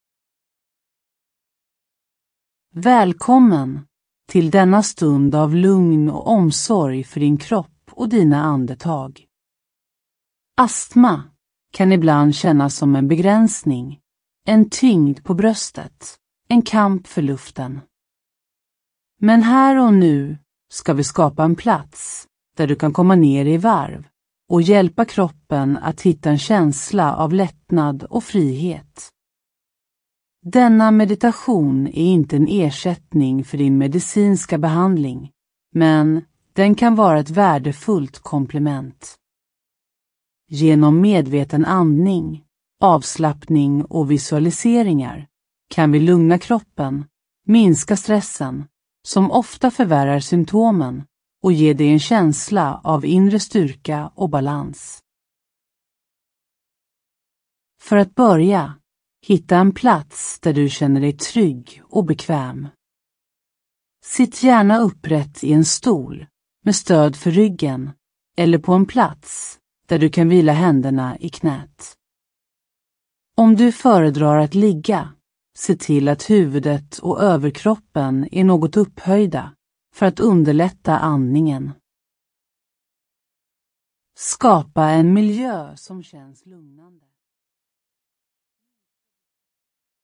Ljudbok
Denna guidade meditation är utformad för att: